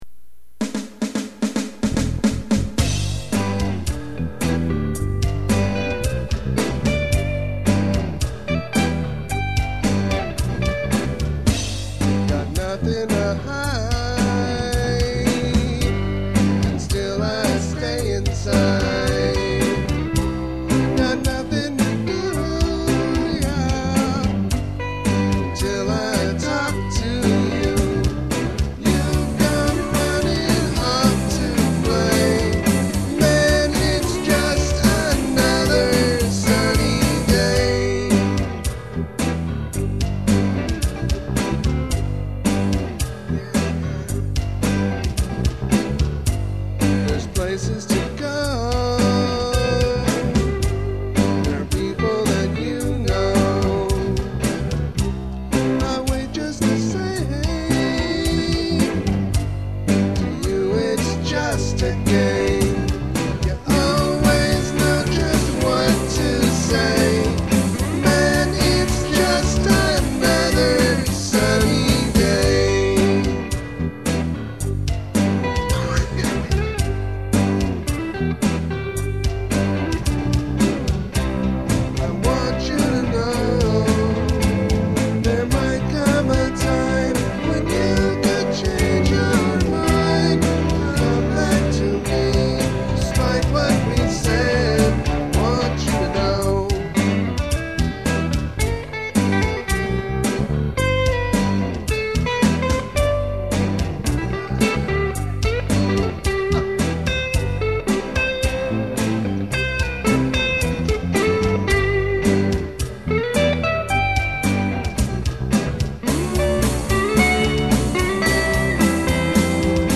Winamp said it was stereo, and it certainly was recorded and posted in stereo, but it sounded like mono to me.